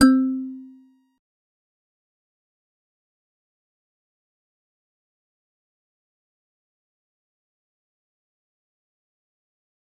G_Musicbox-C4-pp.wav